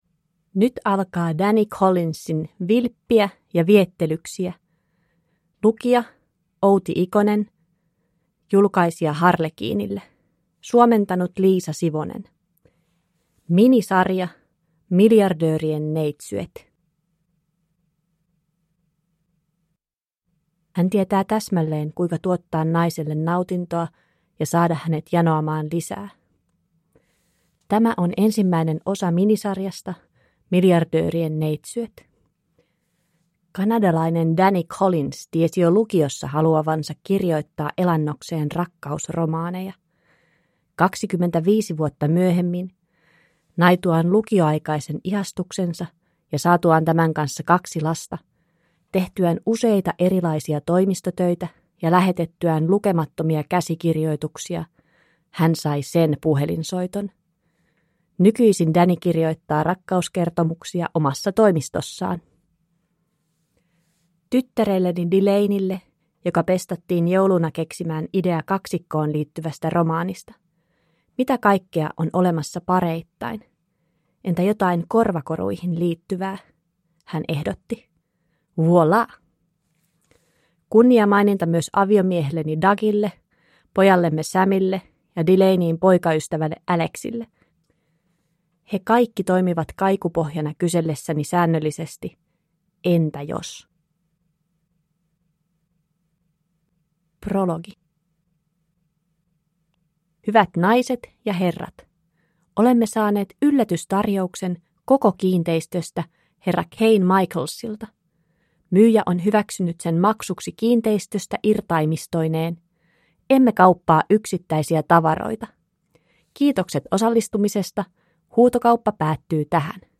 Vilppiä ja viettelyksiä – Ljudbok